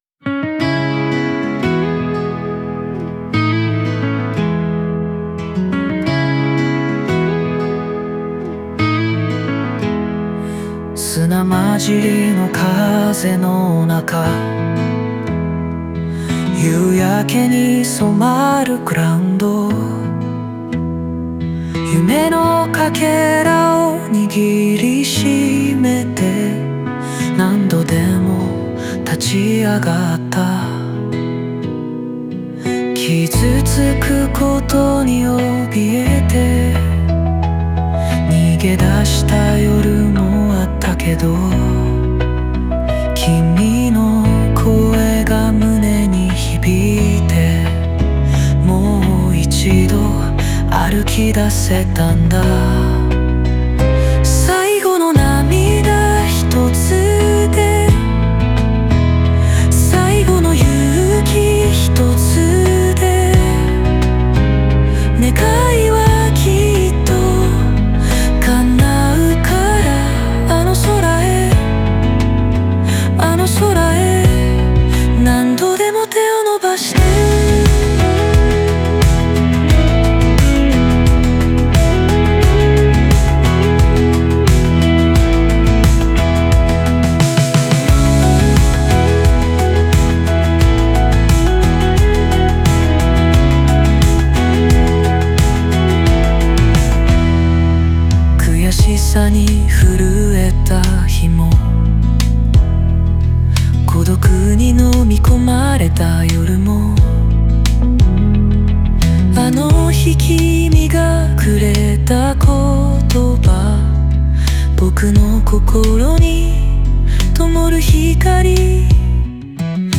オリジナル曲♪
切なさの中にも希望が溢れ、聴く人の背中をそっと押してくれるような楽曲になっています。